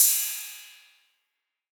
808CY_4_Tape_ST.wav